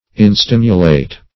Search Result for " instimulate" : The Collaborative International Dictionary of English v.0.48: Instimulate \In*stim"u*late\, v. t. [Pref. in- not + stimulate.]